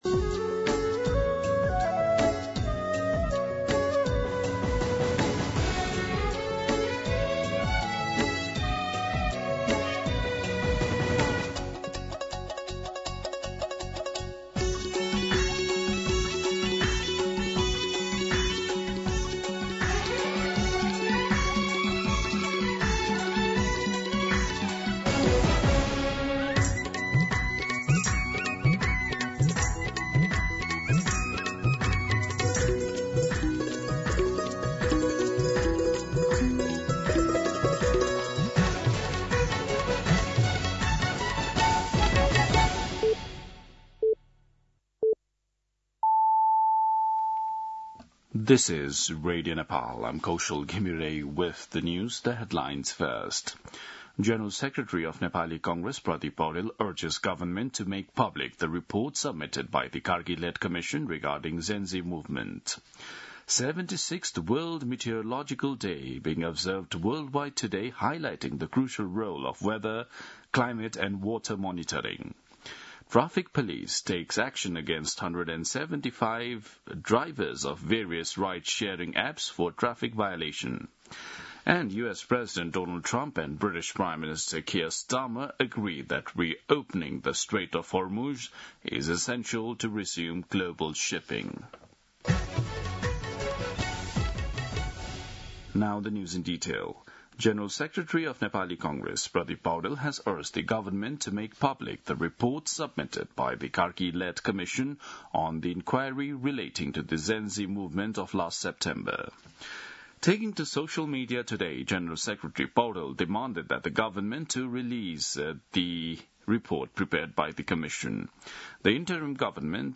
दिउँसो २ बजेको अङ्ग्रेजी समाचार : ९ चैत , २०८२
2pm-English-News-09.mp3